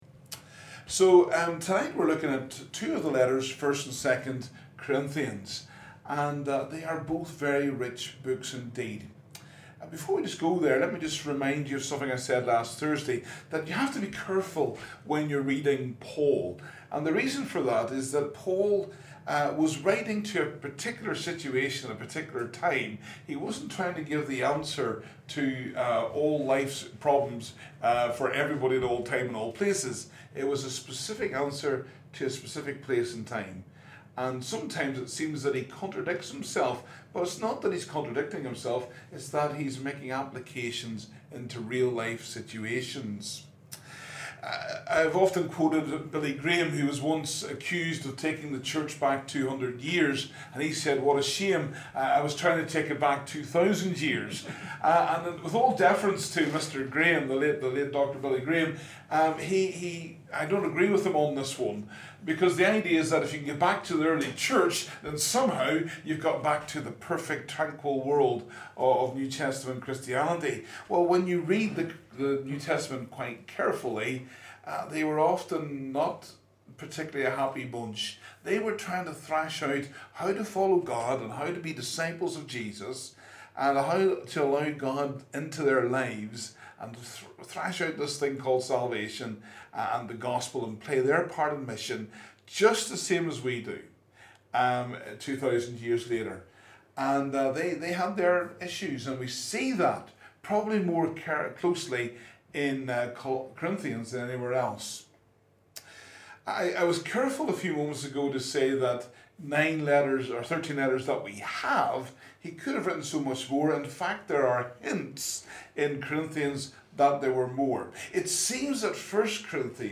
Download the live Session as an MP3 audio file